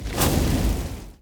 Fireball 3.wav